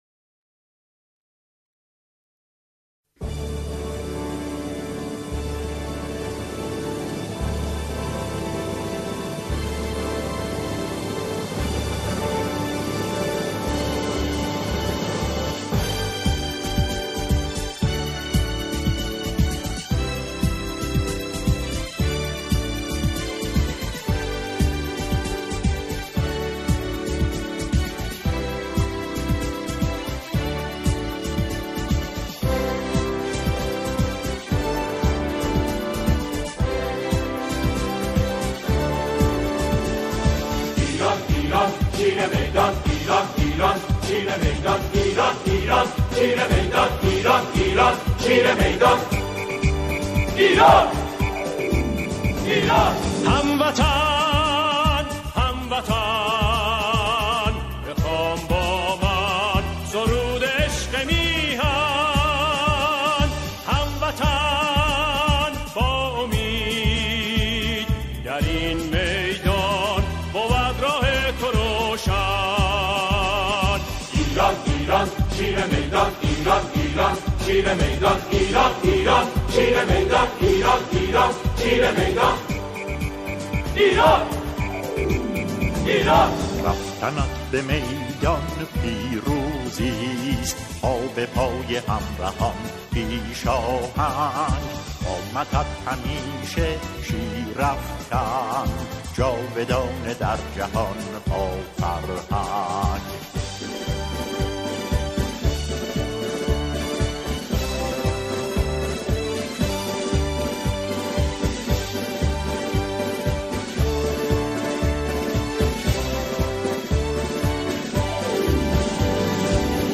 سرودهای ورزشی